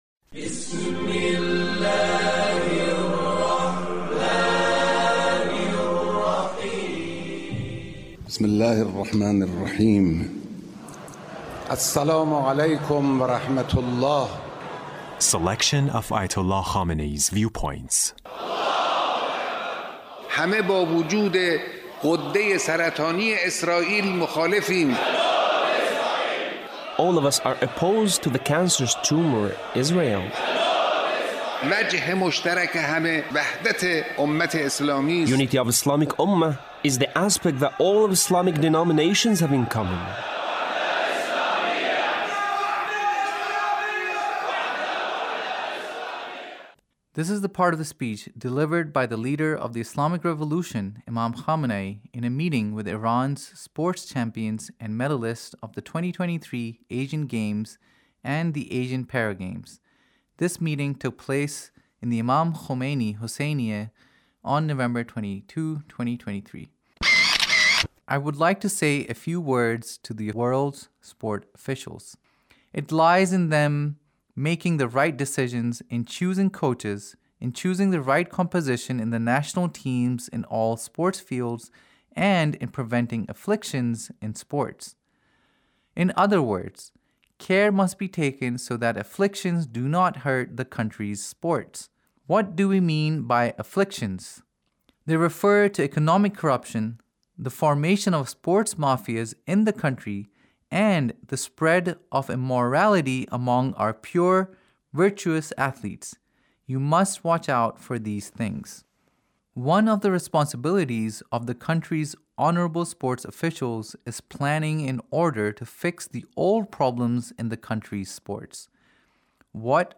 Leader's Speech in a meeting with Iran’s sports champions and medalists